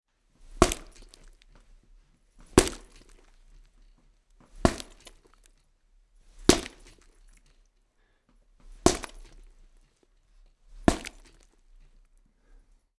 Удары в мясо
Тут вы можете прослушать онлайн и скачать бесплатно аудио запись из категории «Удары, разрушения».